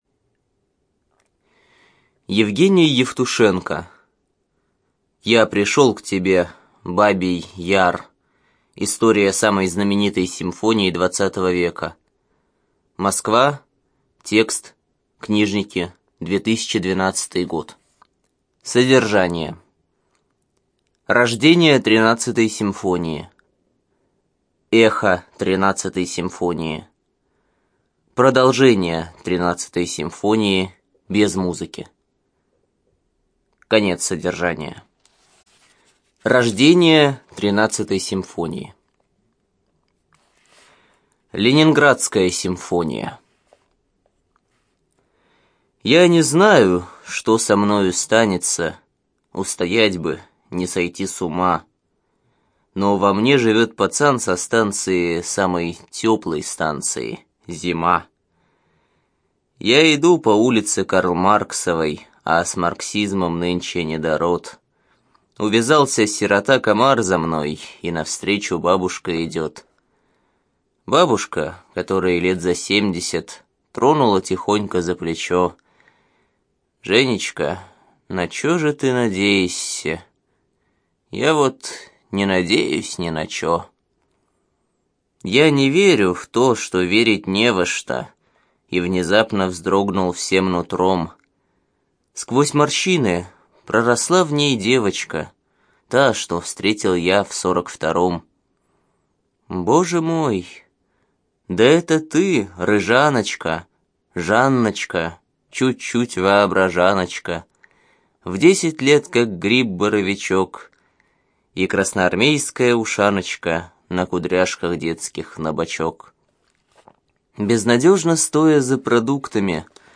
ЖанрПоэзия
Студия звукозаписиЛогосвос